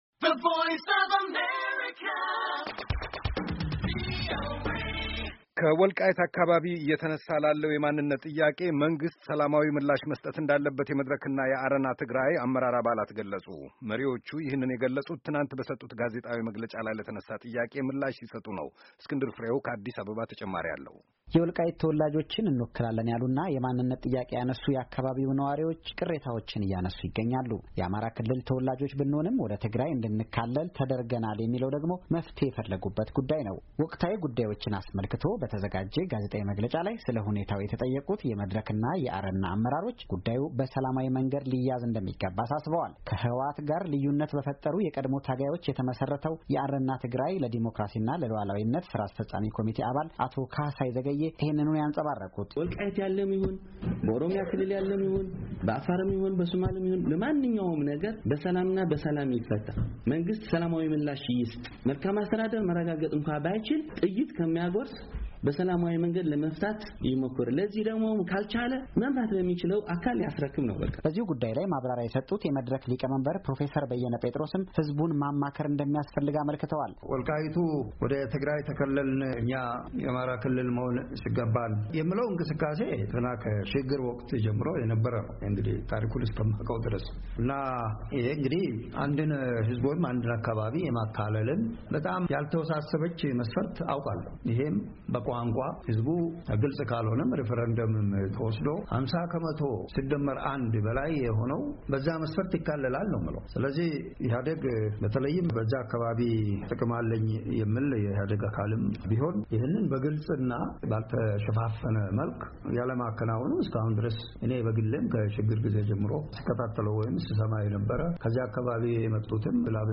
መሪዎቹ ይህንን የገለፁት ትናንት በሰጡት ጋዜጣዊ መግለጫ ላይ ለተነሣ ጥያቄ ምላሽ ሲሰጡ ነው፡፡